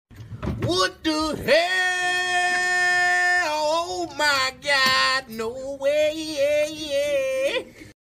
Funny what the hell sound sound effects free download
Funny what the hell sound effect